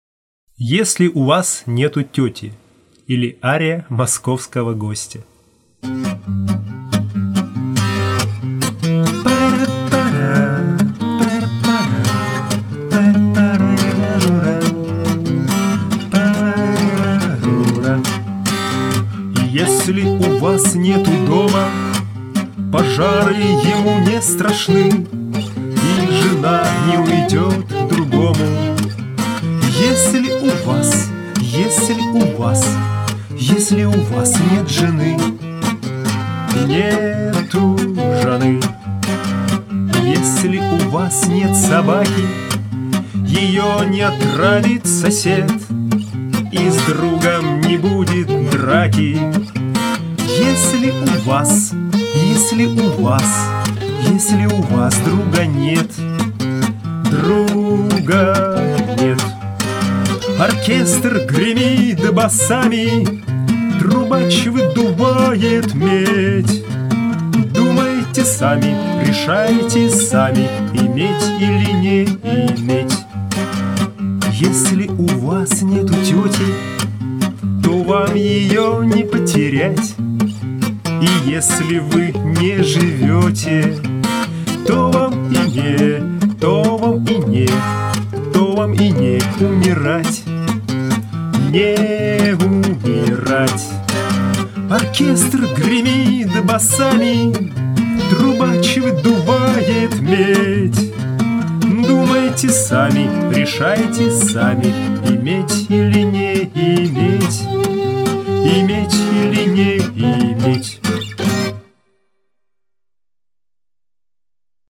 Это когда инструмент дублирует те ноты, которые мы поем (эффект караоке). В данном случае скрипка и скрипичное пиццикато.